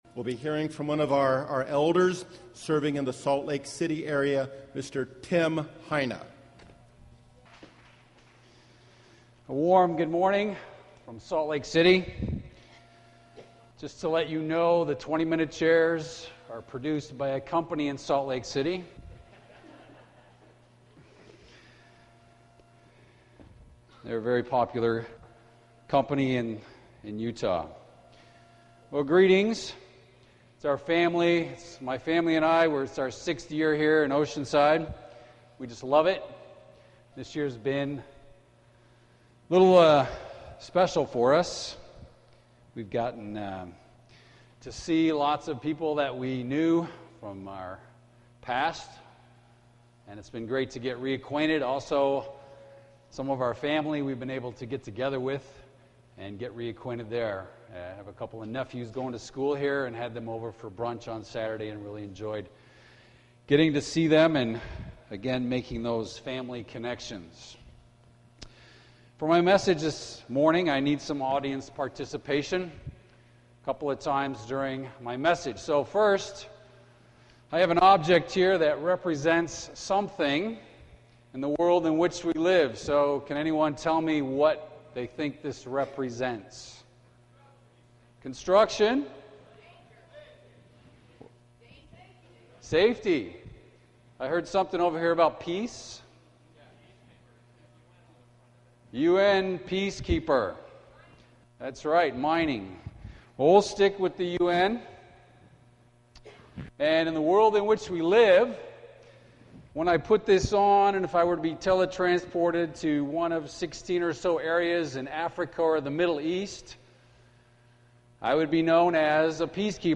This sermon was given at the Oceanside, California 2013 Feast site.